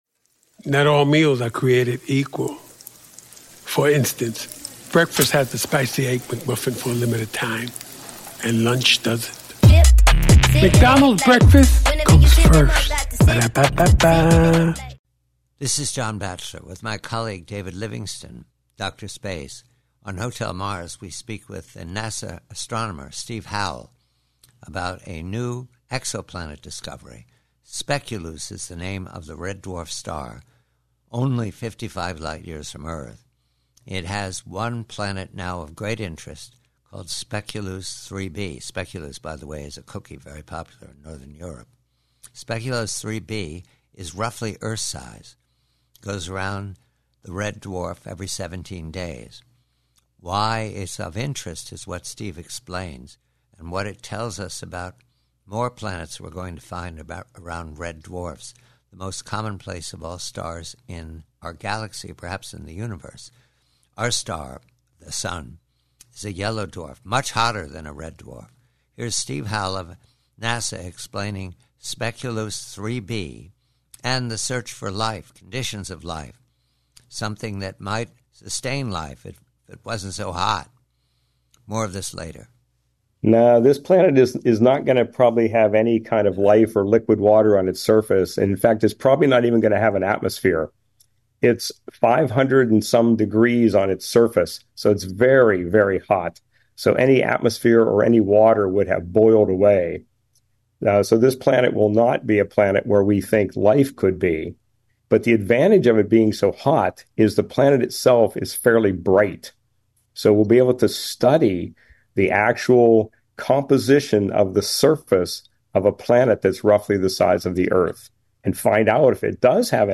PREVIEW: EXOPLANETS: Conversation